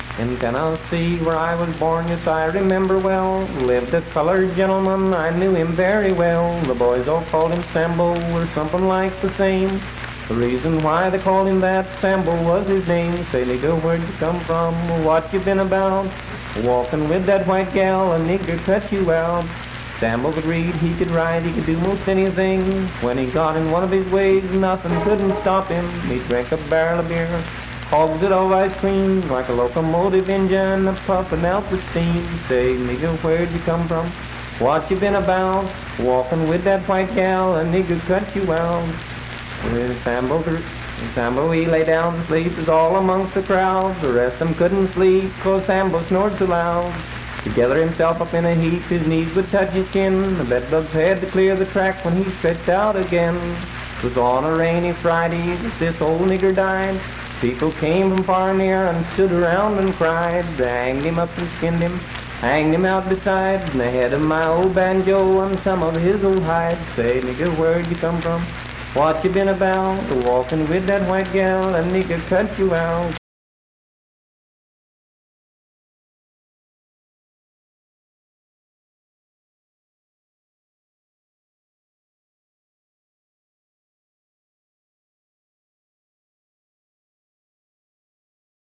Unaccompanied vocal performance
Minstrel, Blackface, and African-American Songs
Voice (sung)
Roane County (W. Va.), Spencer (W. Va.)